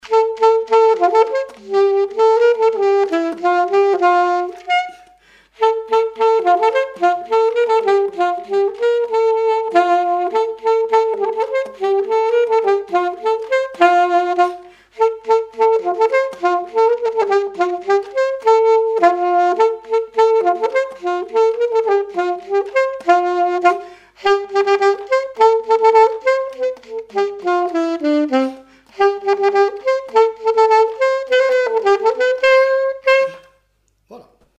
Thorigny
danse : quadrille : moulinet
témoignages et instrumentaux
Pièce musicale inédite